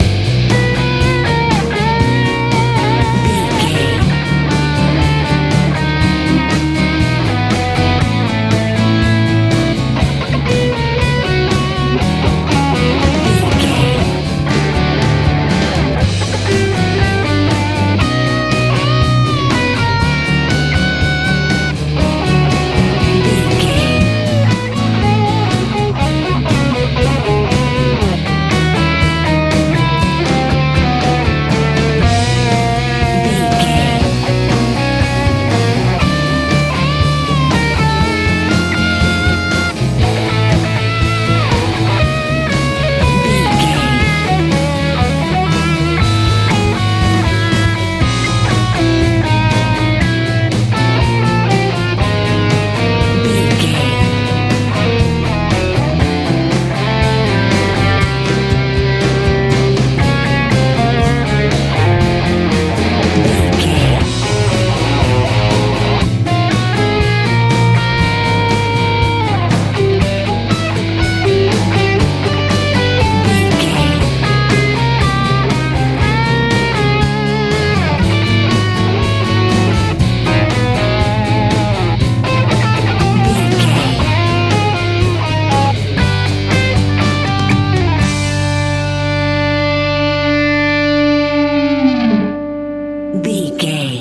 Ionian/Major
driving
energetic
powerful
bass guitar
electric guitar
drums
lively
optimistic